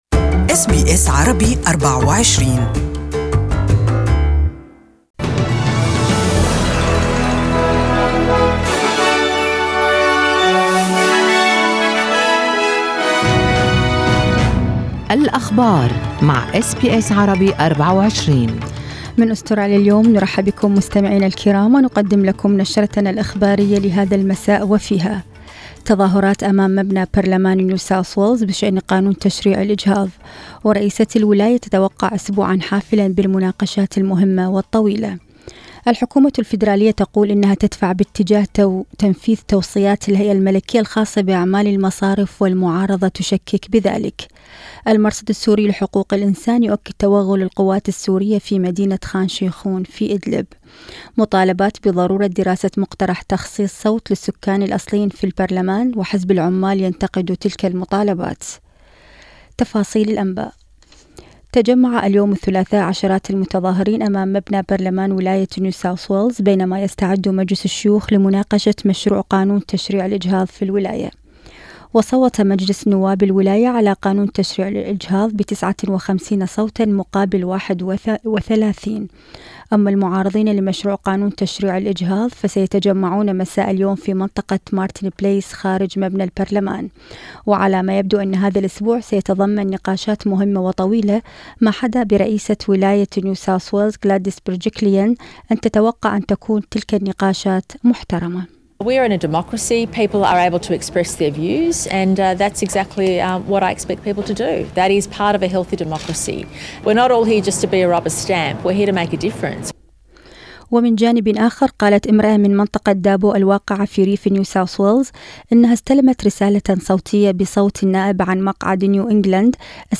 Evening News: Anger as NSW premier delays abortion bill vote for several weeks